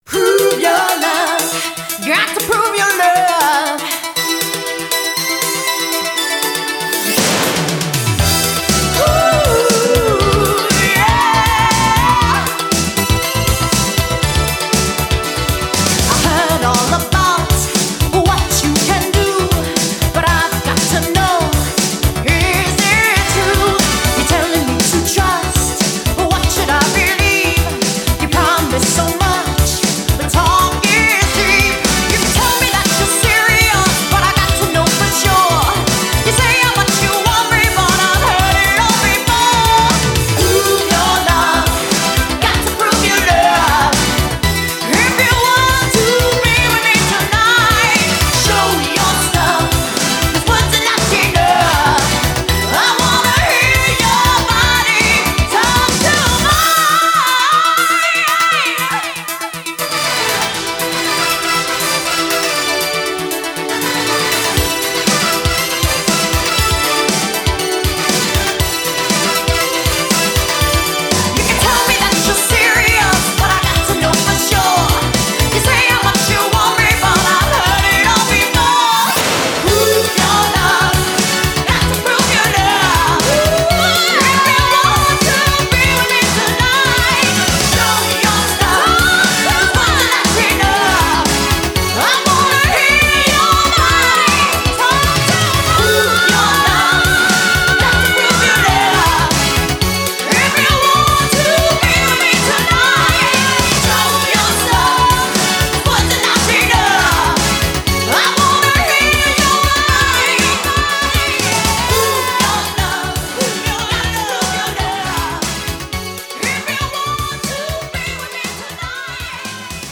BPM119
MP3 QualityMusic Cut